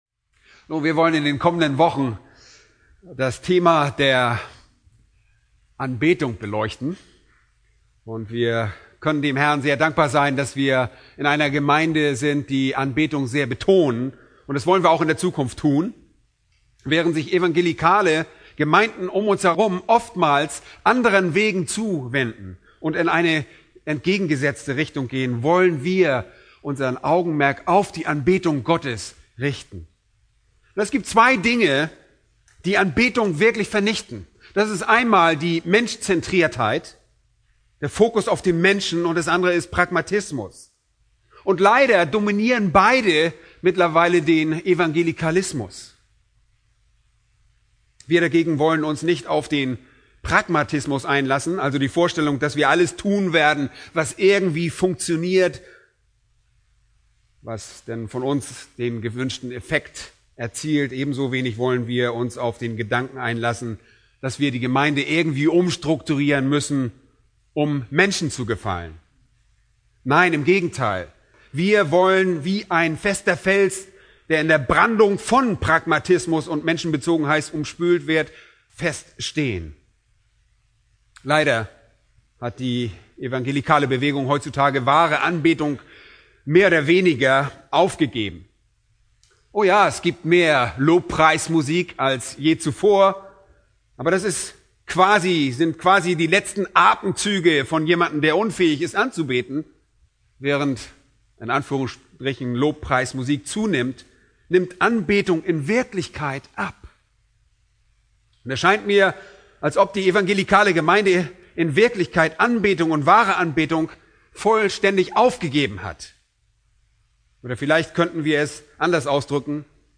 Predigt: "1. Kor. 12,8-11